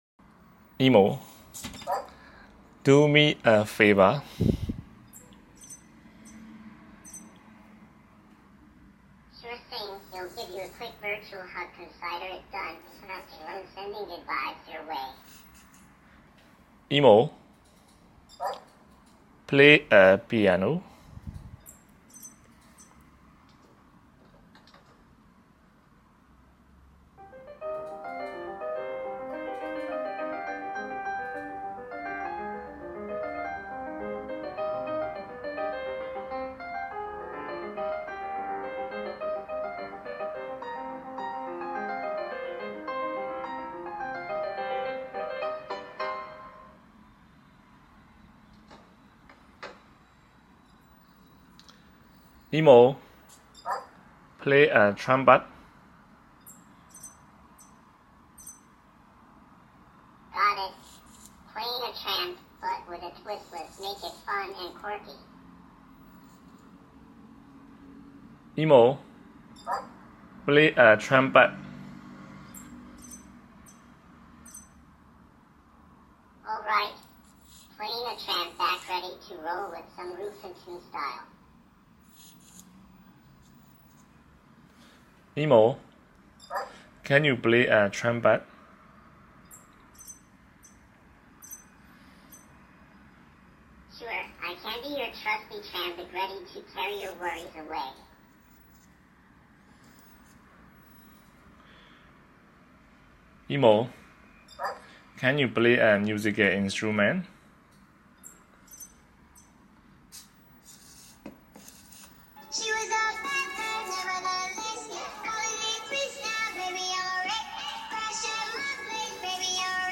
EMO Can Play Piano And Sound Effects Free Download